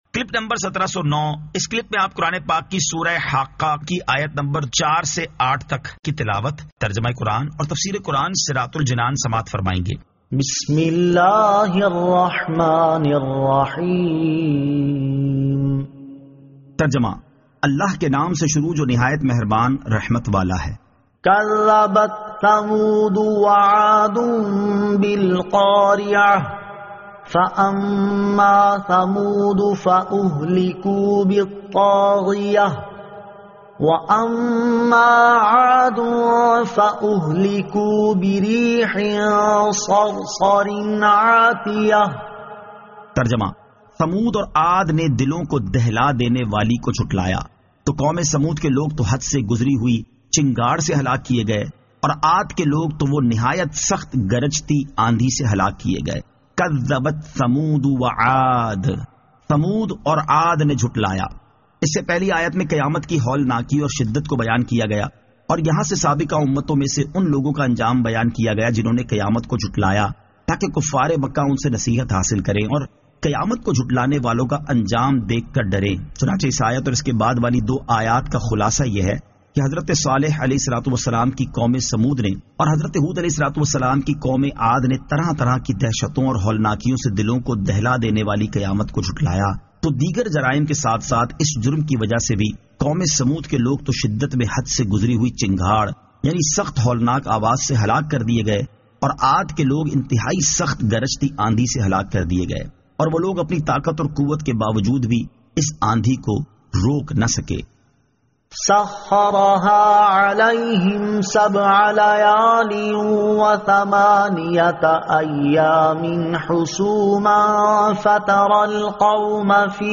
Surah Al-Haqqah 04 To 08 Tilawat , Tarjama , Tafseer